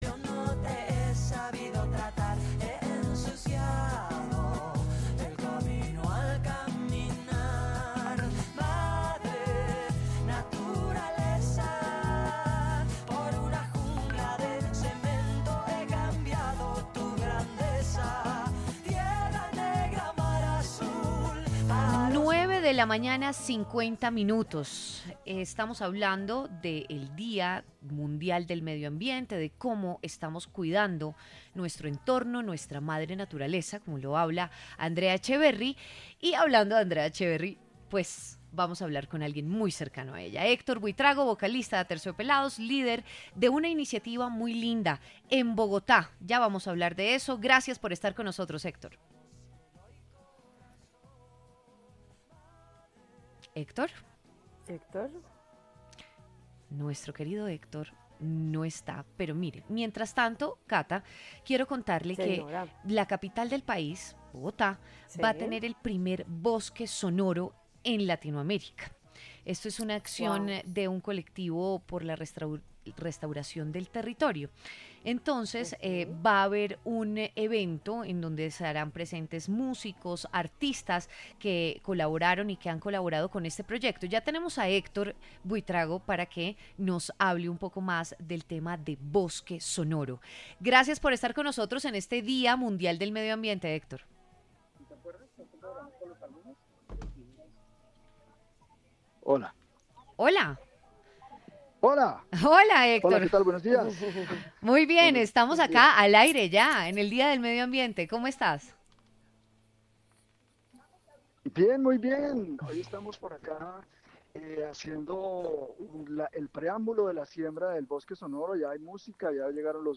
Héctor Buitrago, vocalista de Aterciopelados y líder de la iniciativa, habló en W Fin de Semana sobre “Bosque Sonoro”.